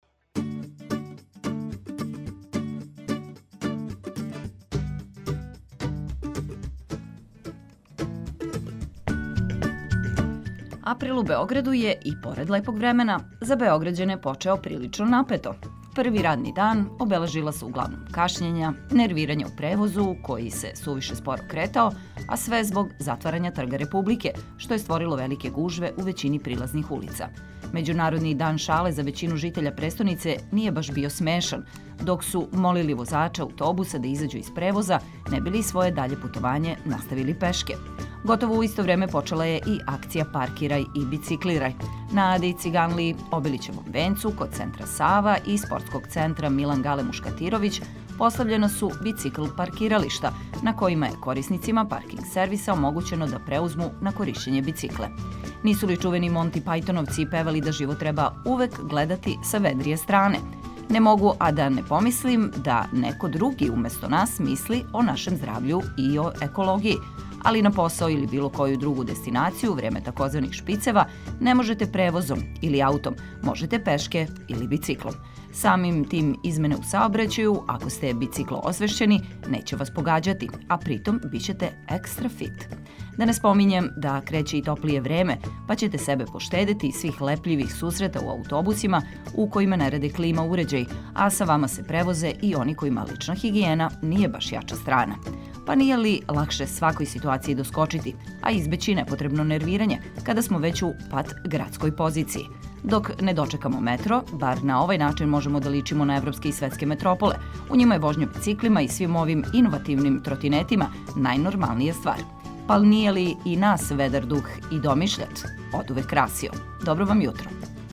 У нови дан крећемо уз обиље корисних информације и много добре музике.